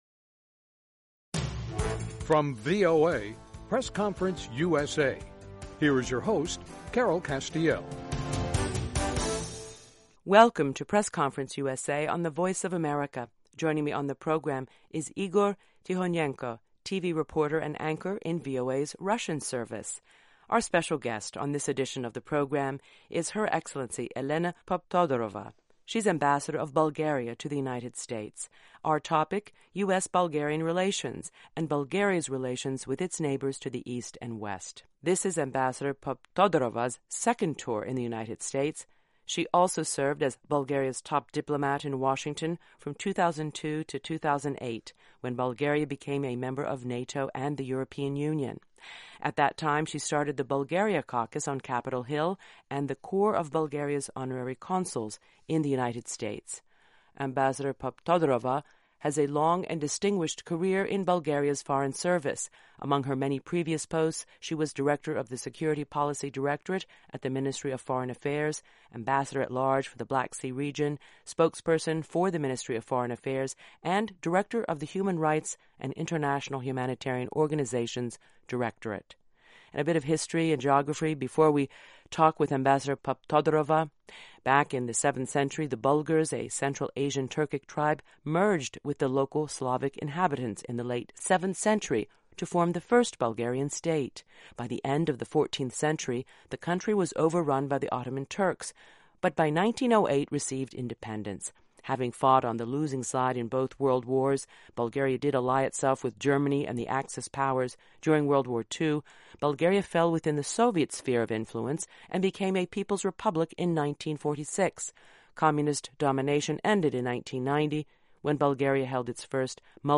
ELENA POPTODOROVA On this edition of the program, a conversation with Bulgarian Ambassador to the U.S., Elena Poptodorova, about Russia's provocative moves in Ukraine. Bulgaria is a member of both the EU and NATO.